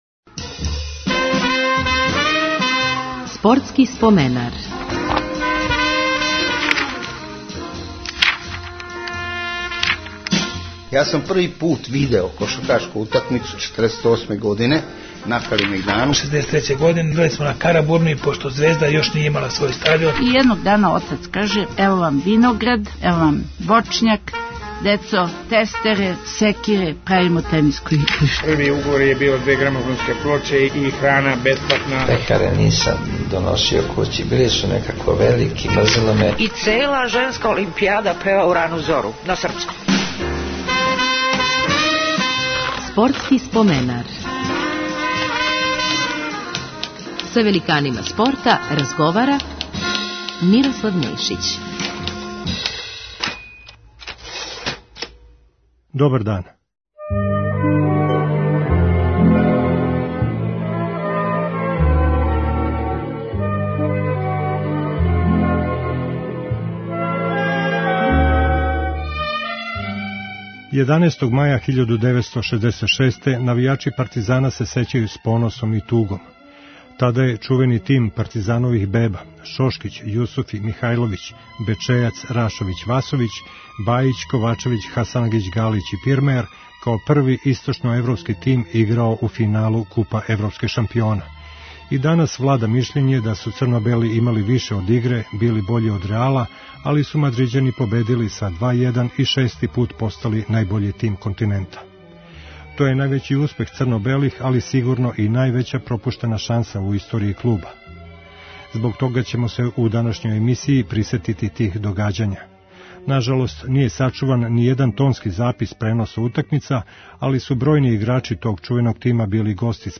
Већина чланова генерације „Партизанових беба" су били гости Спортског споменара, па ће инсери из тих разговора бити искоришћени за ову емисију.